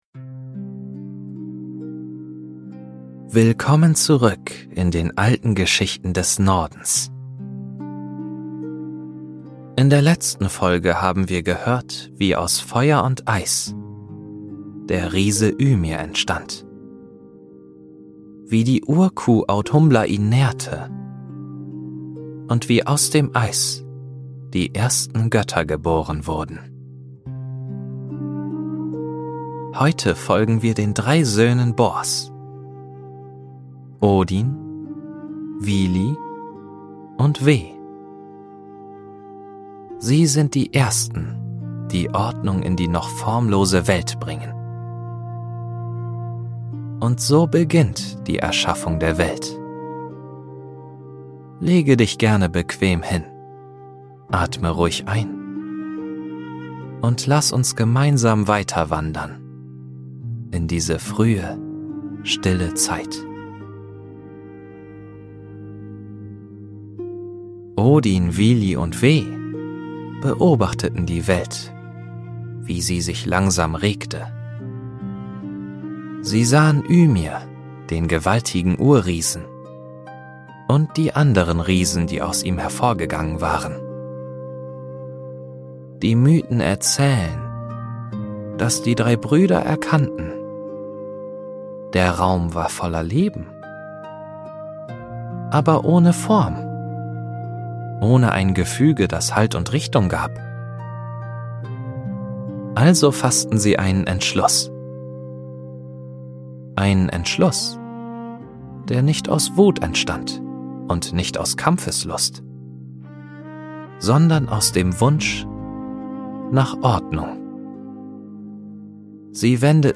Erfahre, wie aus Ymirs Körper die Erde, das Meer und das weite Himmelszelt geformt wurden. Eine Geschichte über den Übergang vom Chaos zur ersten Ordnung, die dich sanft in den Schlaf begleitet.